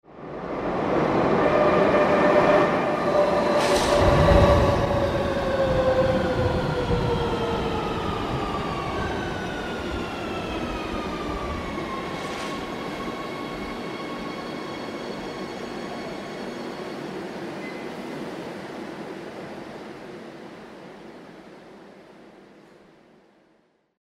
ko2_metro.mp3